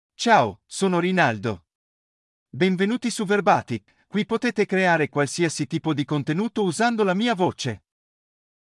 RinaldoMale Italian AI voice
Rinaldo is a male AI voice for Italian (Italy).
Voice sample
Male
Rinaldo delivers clear pronunciation with authentic Italy Italian intonation, making your content sound professionally produced.